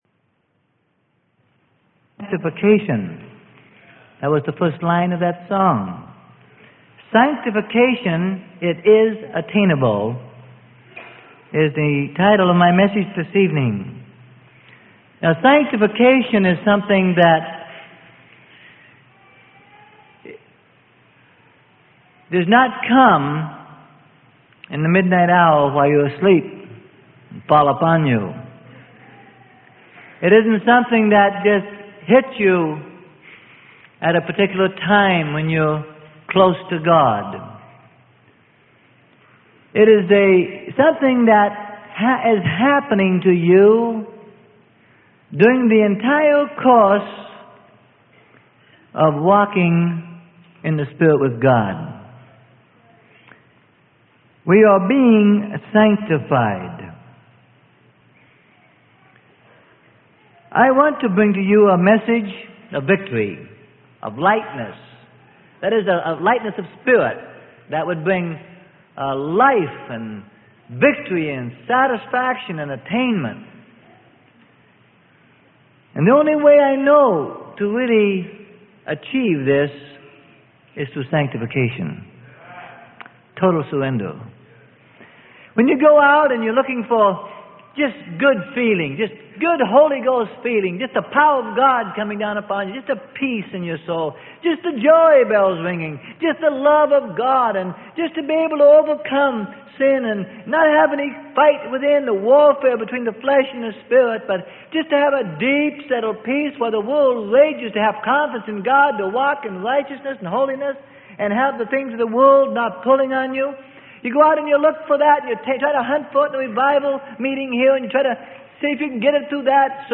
Sermon: Sanctification: It Is Attainable.